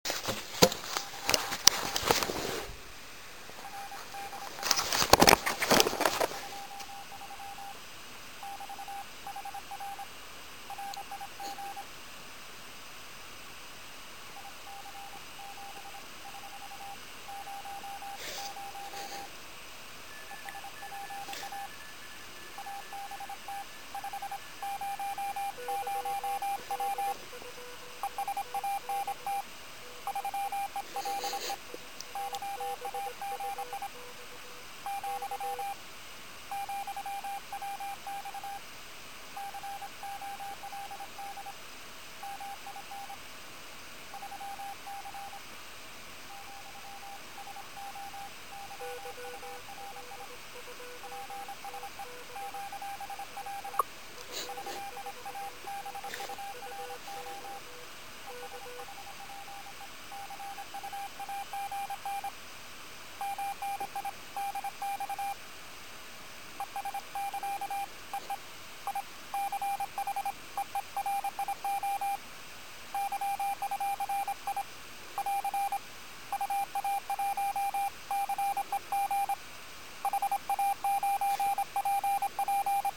Т.е. помех было сколько угодно, и даже более того!